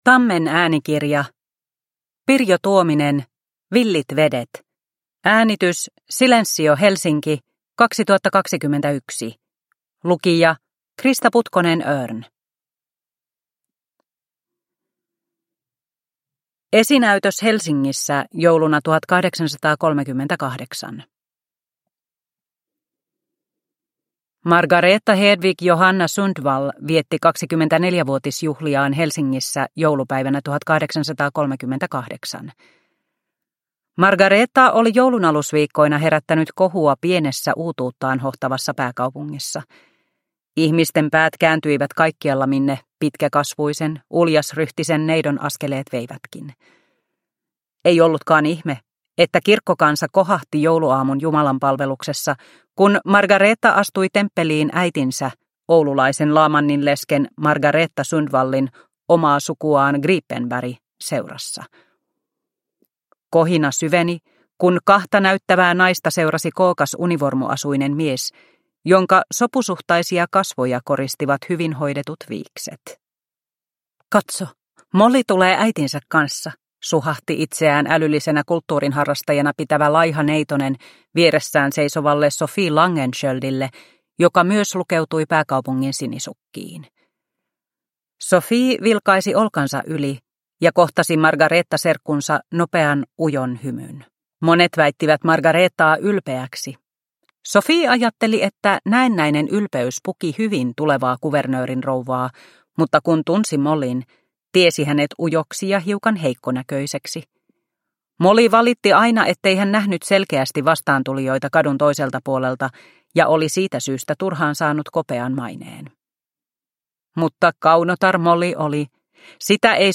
Villit vedet – Ljudbok